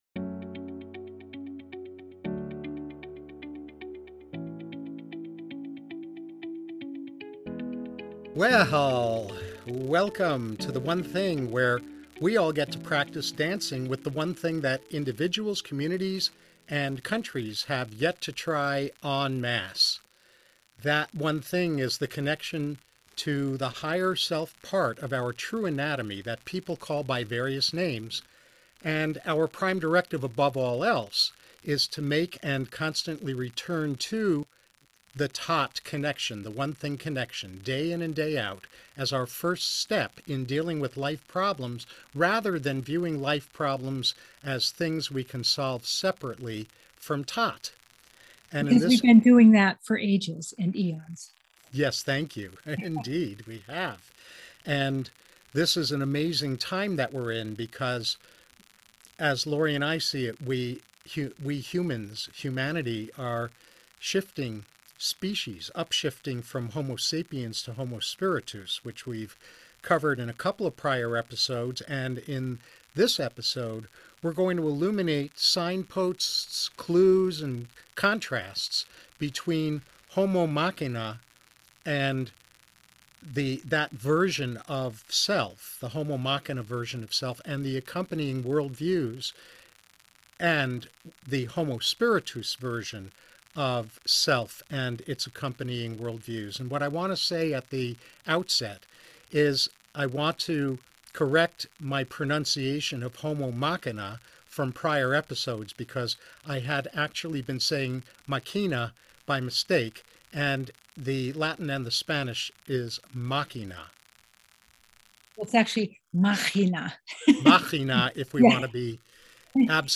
In this episode, we illuminate signposts, clues and contrasts between the Homo Machina version of self and its accompanying worldviews, and the Homo Spiritus version of this. (The static of unknown origin toward the start fades out after a few minutes.)